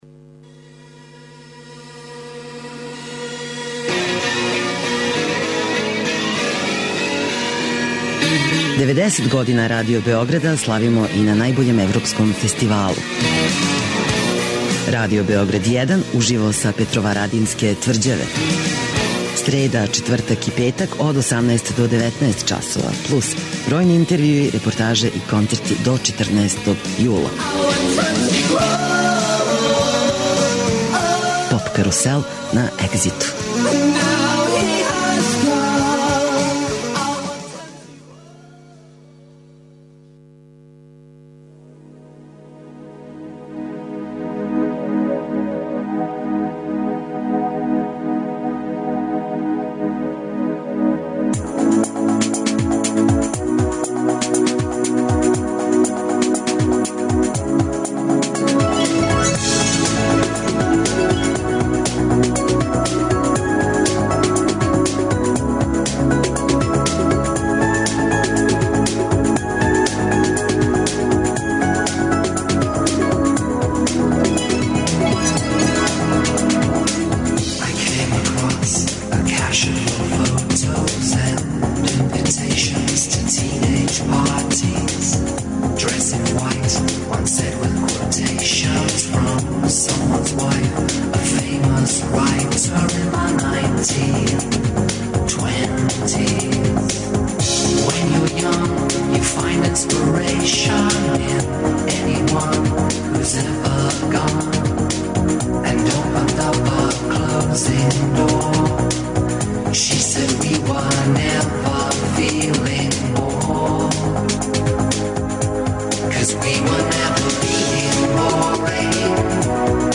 Сваког дана резимирамо претходну ноћ, слушамо извођаче и преносимо део атмосфере.